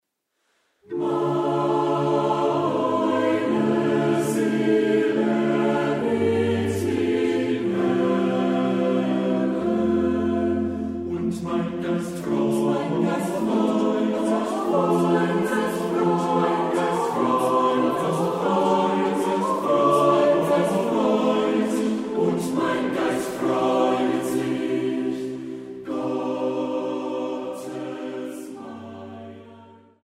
Marienmusik aus dem evangelischen Dom St. Marien zu Wurzen
Orgel